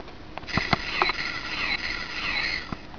The left button gives the sound a somewhat "warbly" characteristic, and the right button gives the sound a more "powerful" feel to it.
WAVE file (.wav extension) allowing you to hear the phaser mouse "firing".